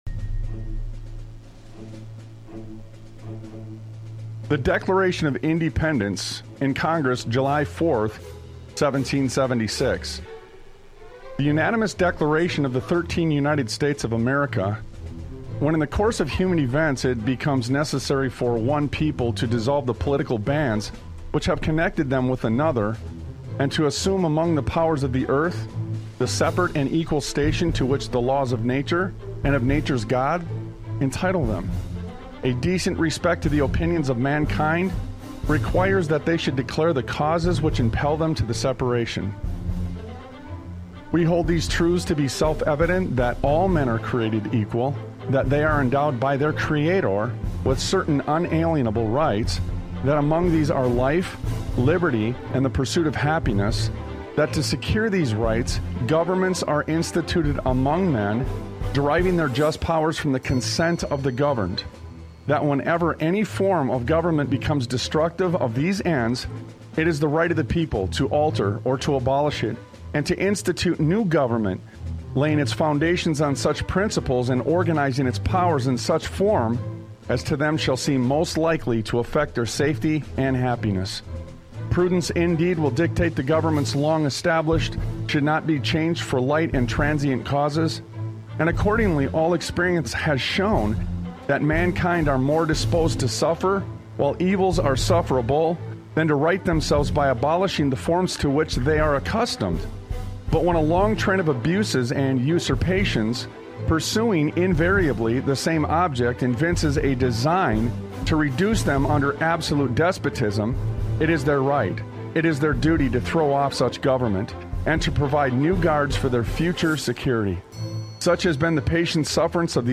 Talk Show Episode, Audio Podcast, Sons of Liberty Radio and Former Minnesota Governor Pawlenty to Newsmax: Walz Is The 'Fraud Father' on , show guests , about Former Minnesota Governor Pawlenty to Newsmax: Walz Is The Fraud Father, categorized as Education,History,Military,News,Politics & Government,Religion,Christianity,Society and Culture,Theory & Conspiracy